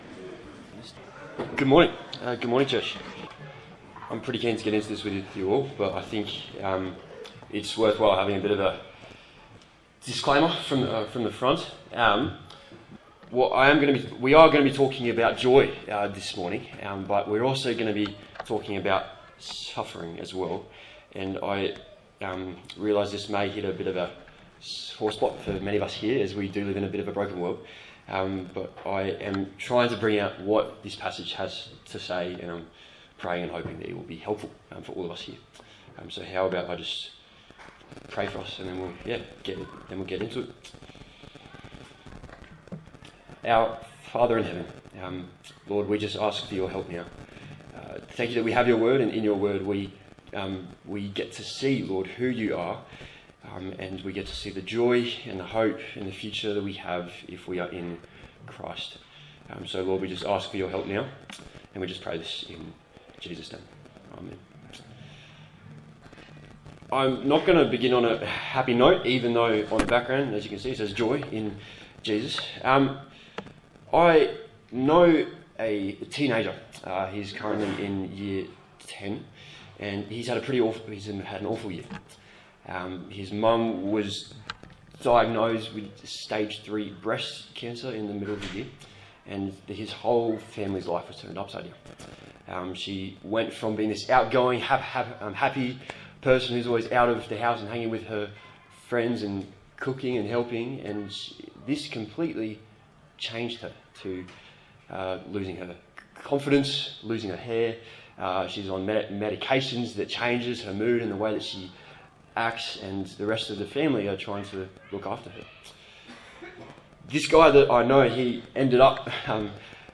Philippians Passage: Philippians 1:18b-26 Service Type: Sunday Morning